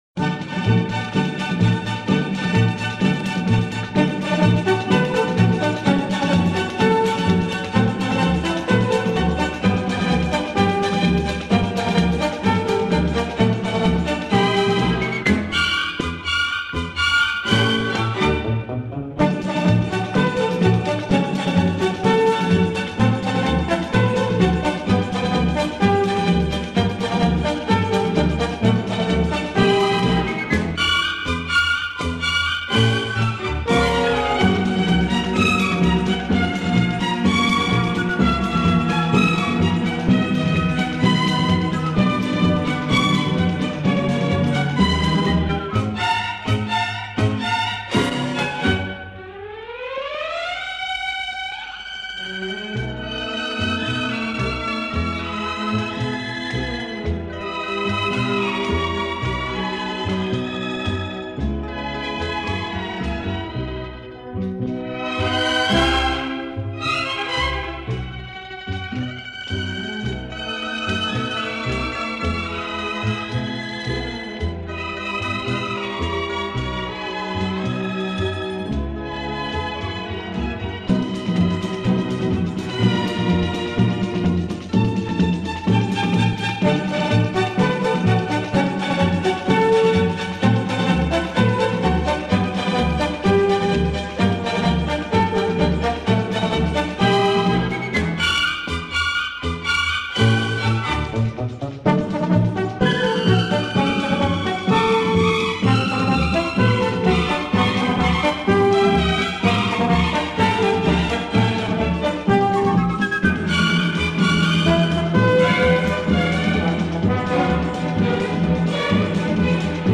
Genre:World Music